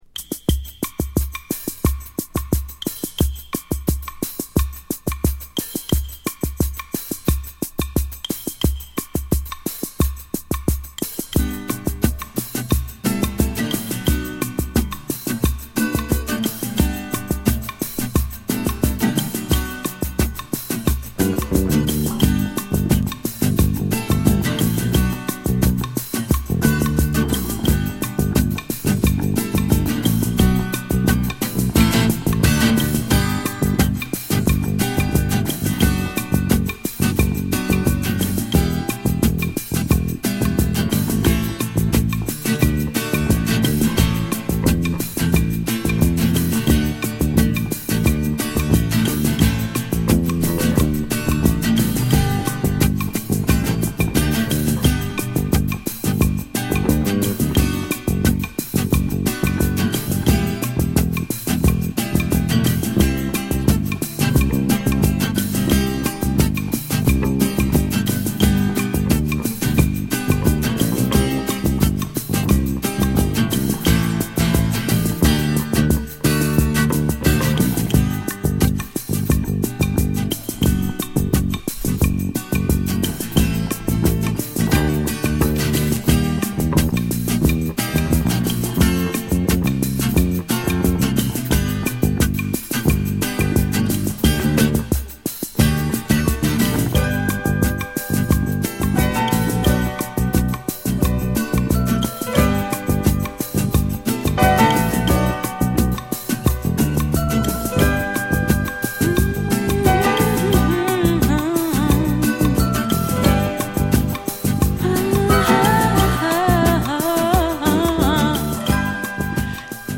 レゲエ、ダブ、ファンク、そしてセカンドライン的なリズム/グルーヴをも内包したミッド・ダンサーA2
80's NYアンダーグラウンドならではのNight Dubbib'なエレクトリック・ファンクの世界を聴かせるB1
グルーヴィーで爽快な高揚に溢れるミッド・ダンサーB2